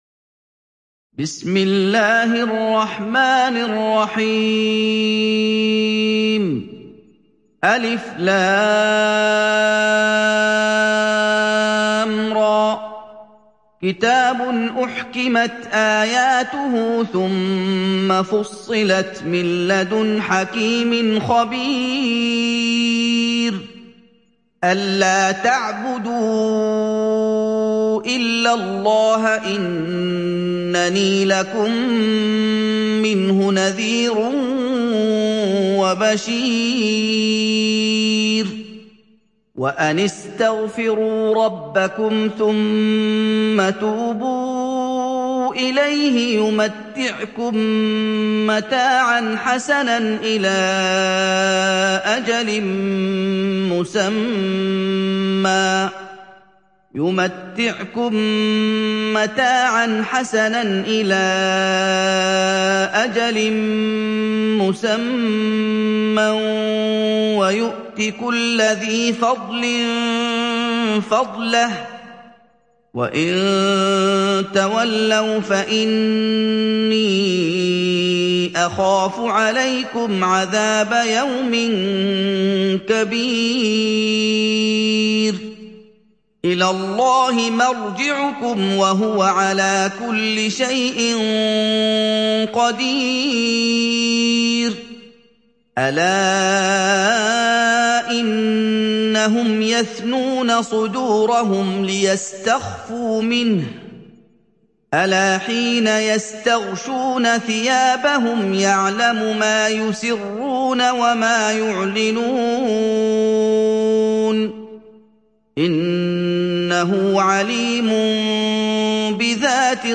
সূরা হূদ ডাউনলোড mp3 Muhammad Ayoub উপন্যাস Hafs থেকে Asim, ডাউনলোড করুন এবং কুরআন শুনুন mp3 সম্পূর্ণ সরাসরি লিঙ্ক